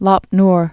(lŏp nr) also Lop Nor (nôr)